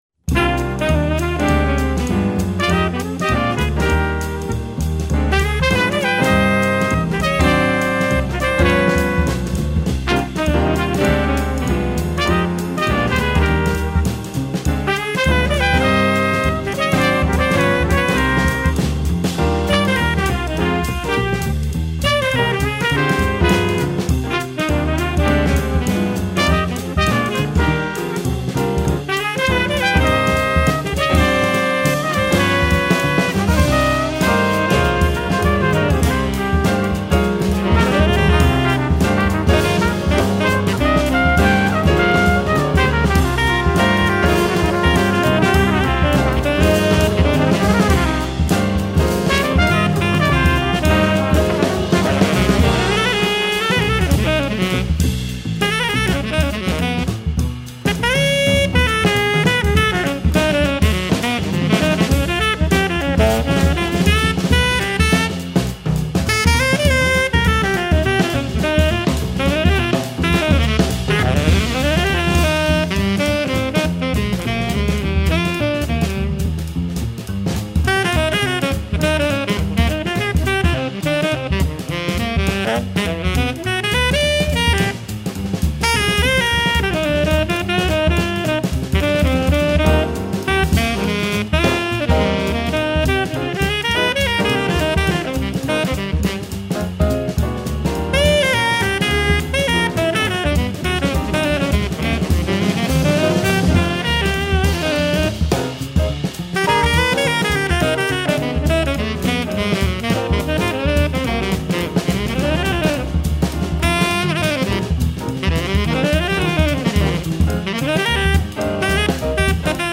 Post-Bop.